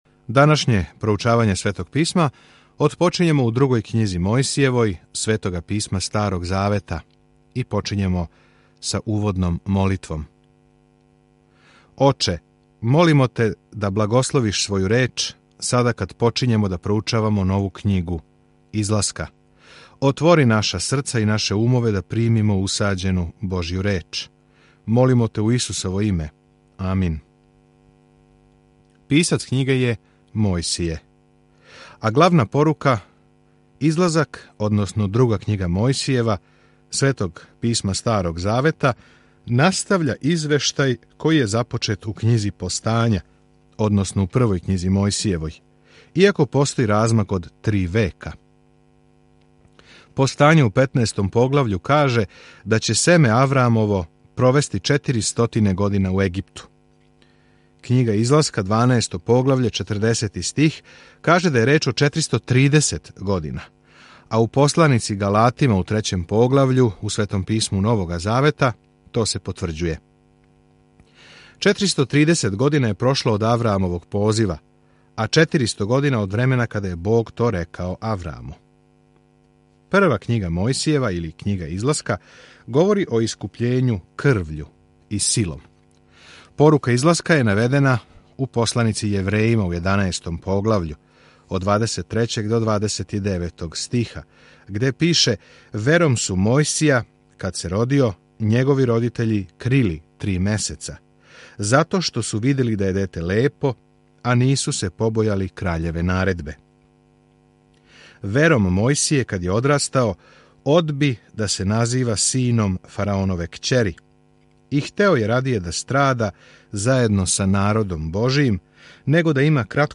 Mojsijeva 1:1-12 Započni ovaj plan Dan 2 O ovom planu Излазак прати бекство Израела из ропства у Египту и описује све што се догодило на том путу. Свакодневно путујте кроз Излазак док слушате аудио студију и читате одабране стихове из Божје речи.